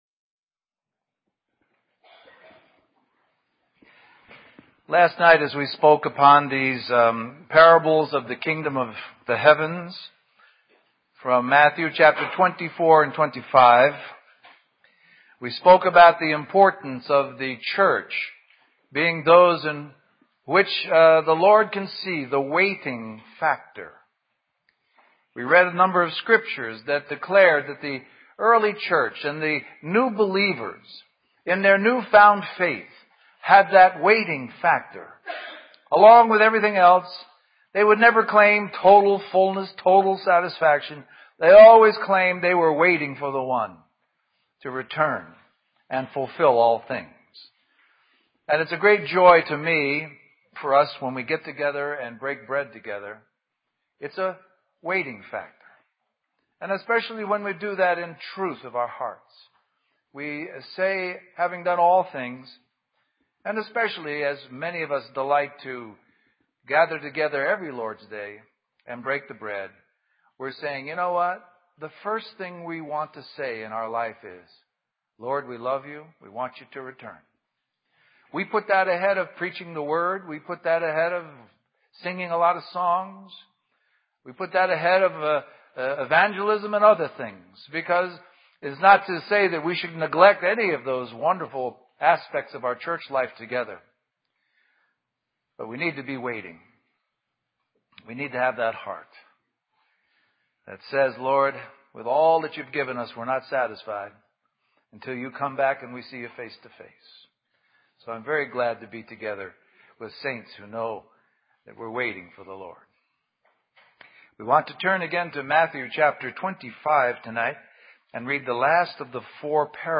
A collection of Christ focused messages published by the Christian Testimony Ministry in Richmond, VA.
Western Christian Conference